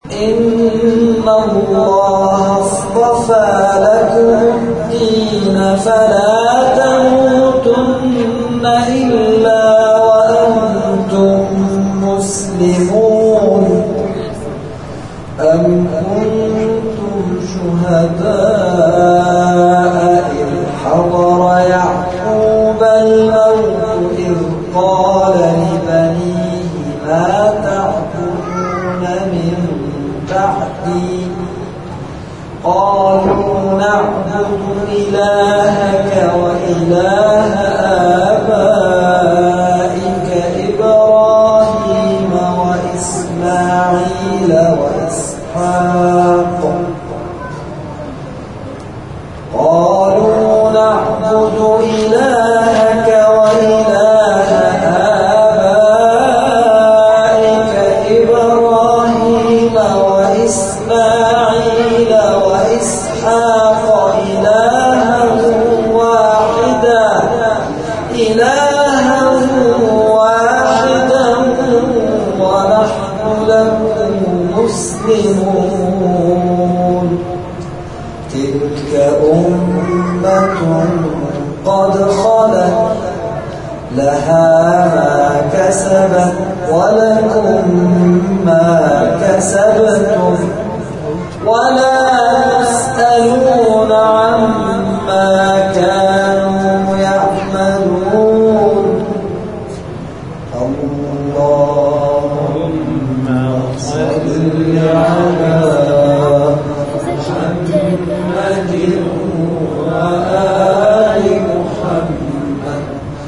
این مراسم جزءخوانی که سابقه 15 ساله دارد، هر شب در ماه مبارک رمضان، از ساعت 22 تا 1 بامداد در حسینیه انصارالحسین(ع) برگزار می‌شود.